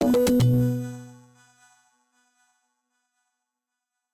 sfx_transition-02.ogg